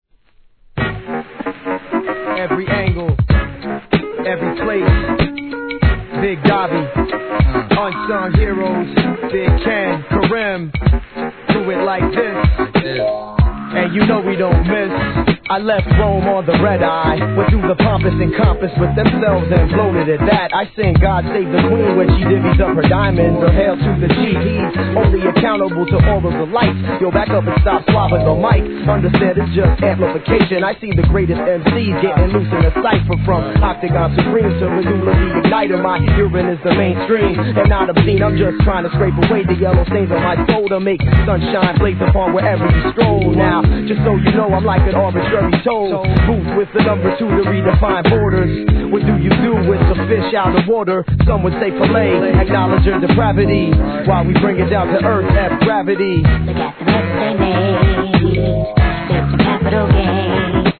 1. HIP HOP/R&B
女性ボーカルをフックに用いたやわらかいイメージがNICEな2000年アンダーグランド!!